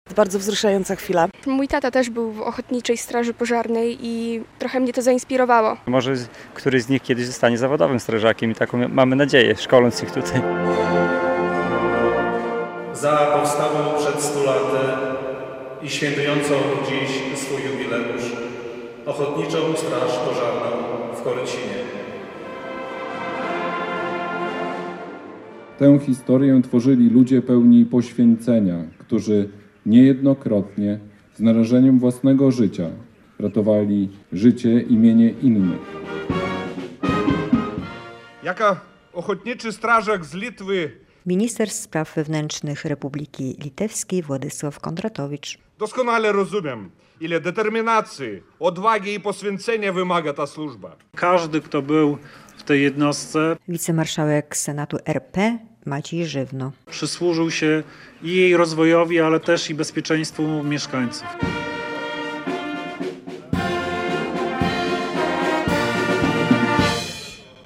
Strażacy ochotnicy z Korycina świętowali 100-lecie utworzenia swojej jednostki - relacja